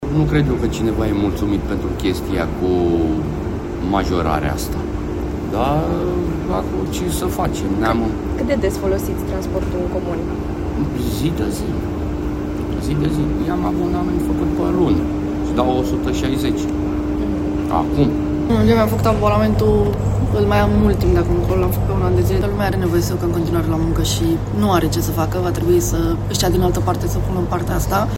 14apr-11-Vox-vom-continua-sa-platim-.mp3